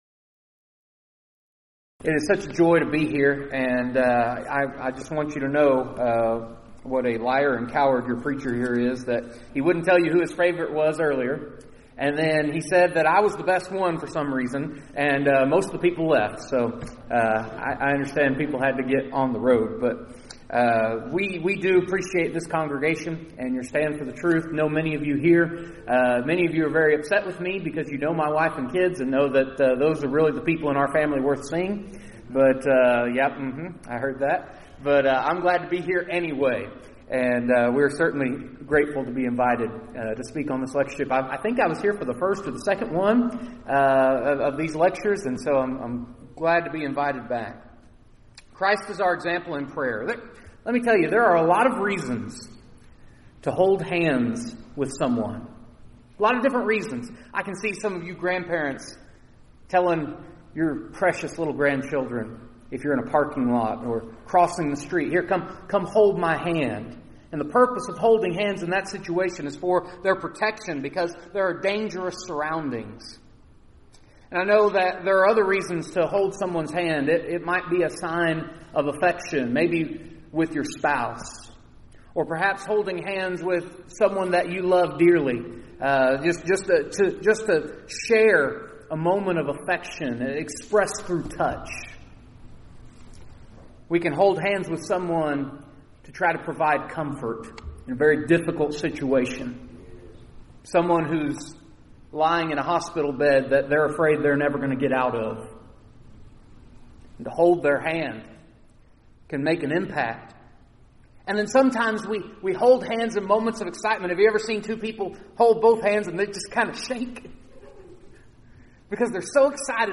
Event: 9th Annual Back to the Bible Lectures